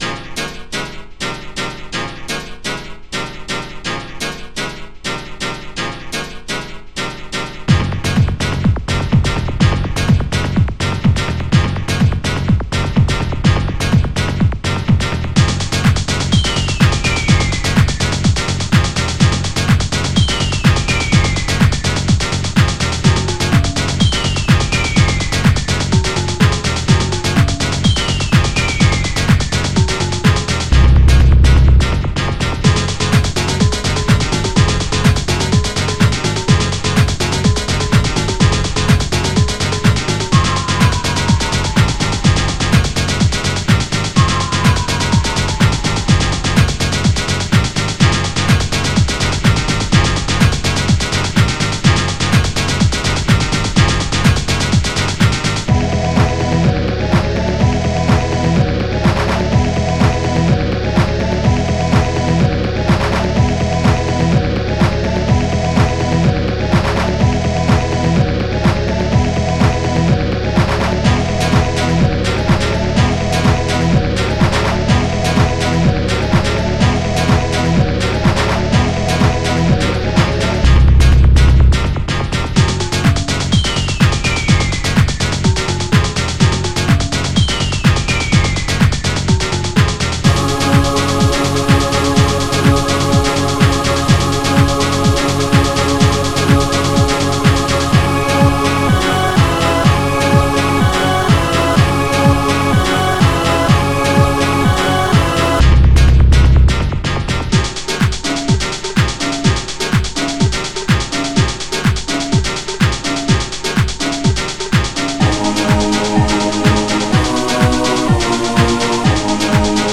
ST-05:hihat-akai
st-21:megabass
st-04:ANIMATE-CLAP
ST-20:abnormchoir